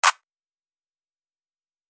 Claps